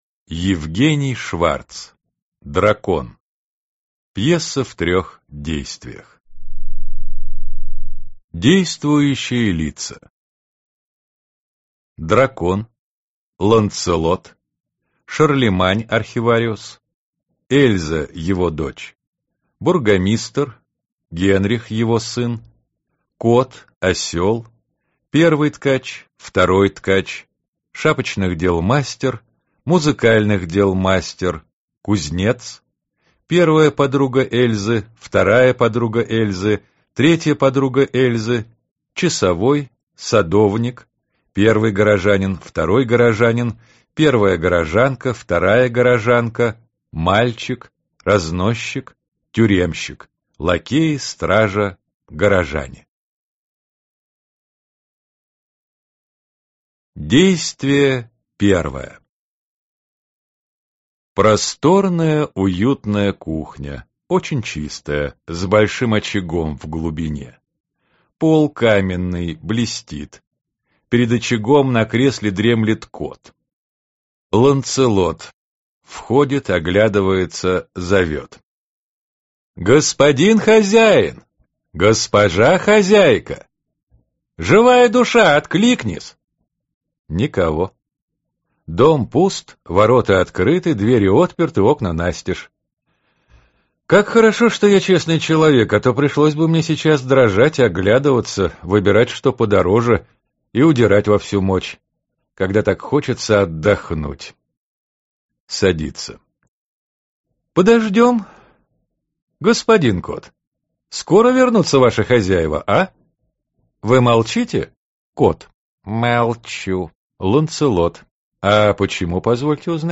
Аудиокнига Дракон | Библиотека аудиокниг
Прослушать и бесплатно скачать фрагмент аудиокниги